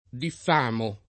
diffamo [ diff # mo ]